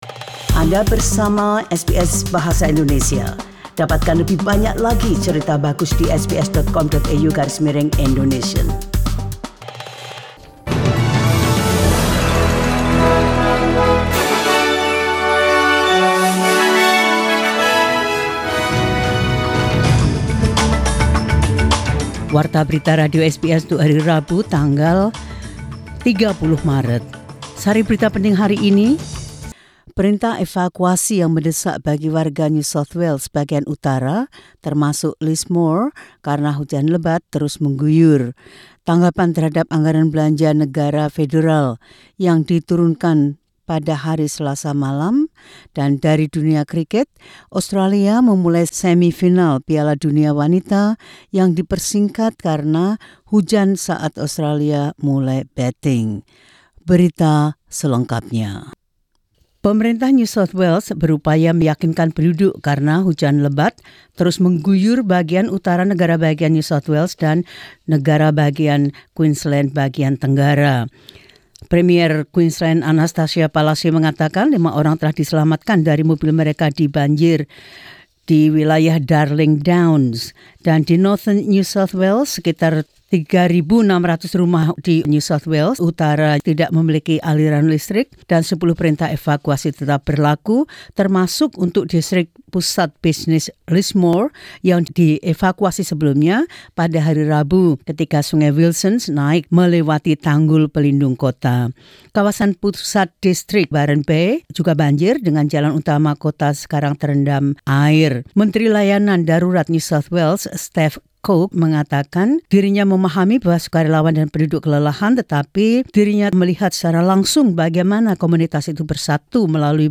SBS Radio news in Indonesian.